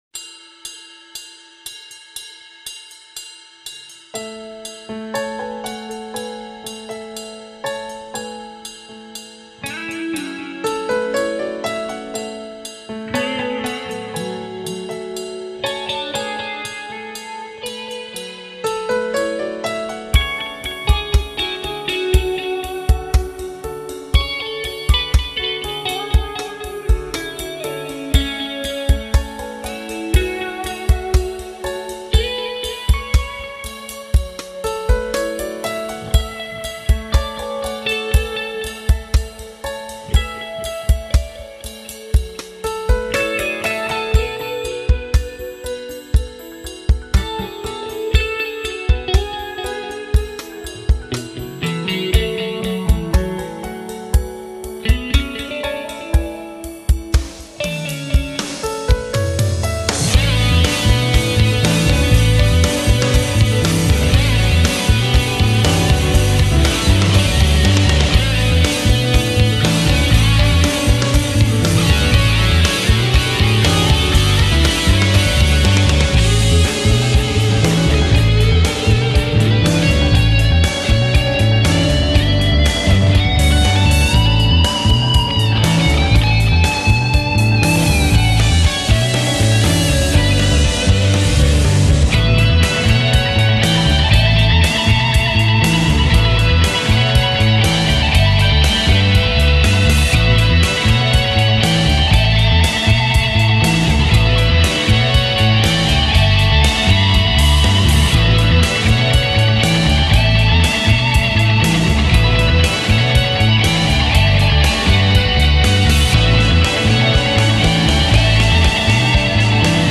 meine Version zum 85er ist auch schon über ein Jahr alt, ich hatte damals meine Thinline Tele mit dem Womanizer benutzt.
In den cleanen Passagen ist dann der Small Stone aktiv, Hall und etwas Delay kamen aus Plugins dazu. Überhaupt hatte ich recht viele Gitarrenspuren hinzugefügt, es gibt noch eine gedoppelte zusätzliche Rhythmusgitarre, verschiedene Leadspuren, zweite Stimmen usw.